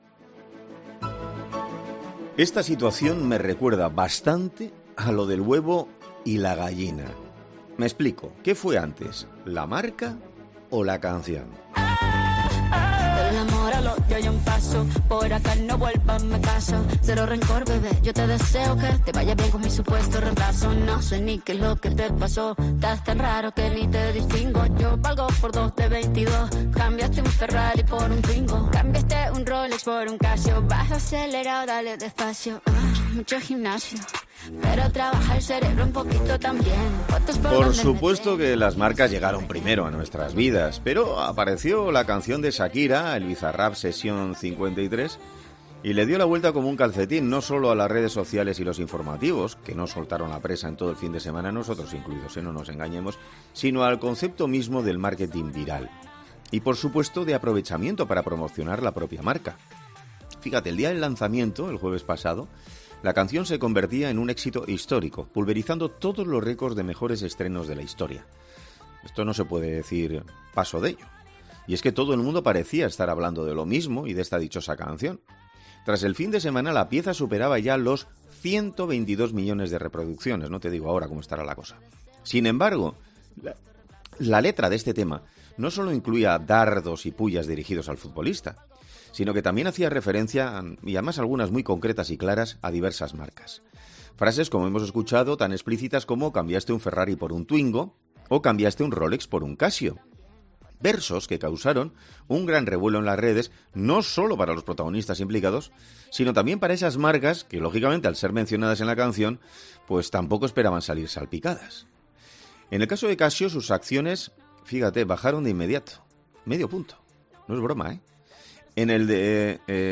'Herrera en COPE' habla con un profesor de marketing que explica como las marcas aprovechan este tipo de momentos virales para sumarse al efecto